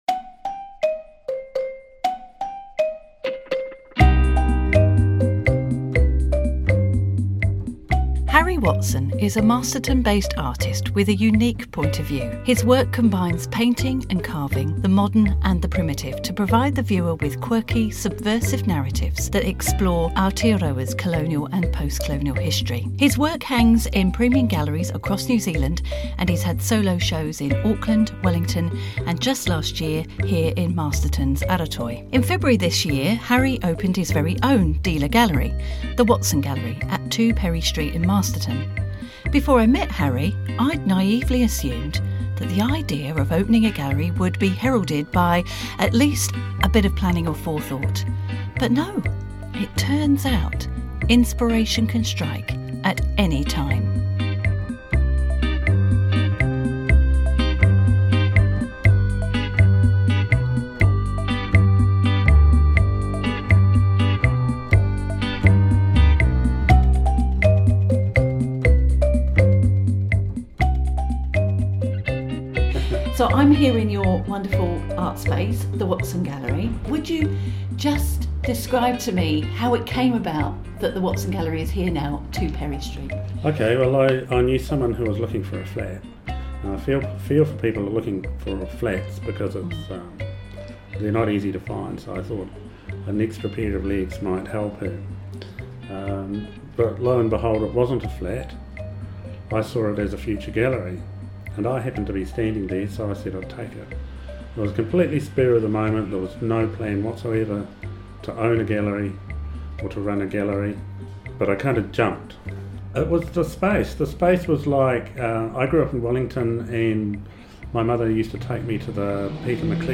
Interview with artist